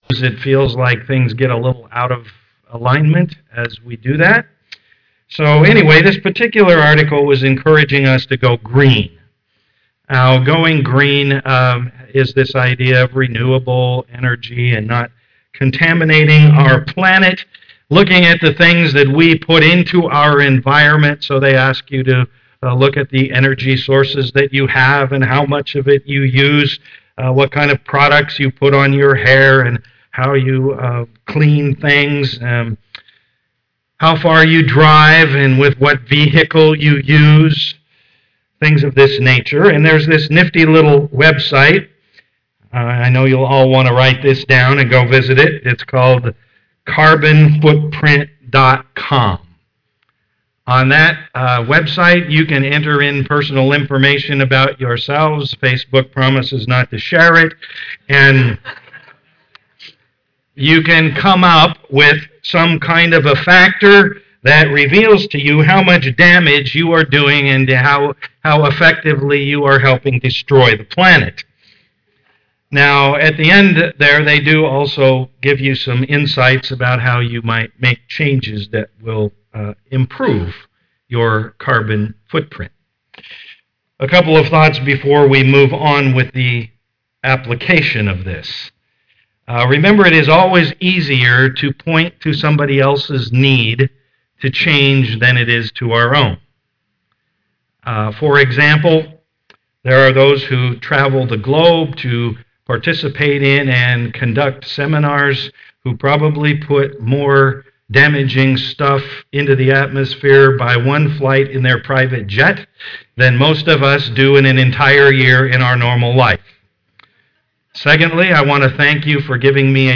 Service Type: am worship Download Files Notes Bulletin Topics: godliness , holiness , motivation « Know God